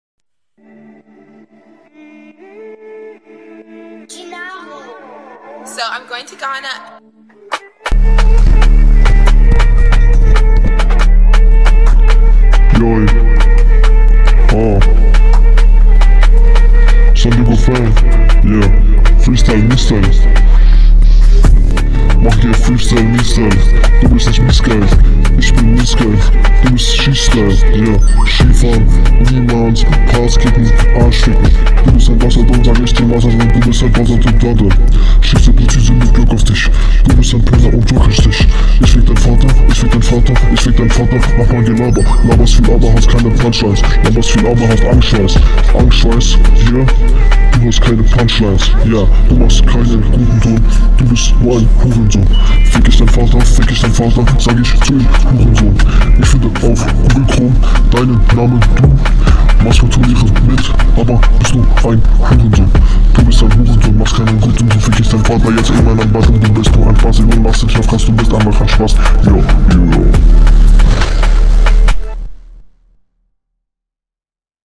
wow man kann was verstehen